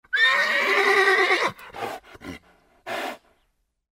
马死亡.mp3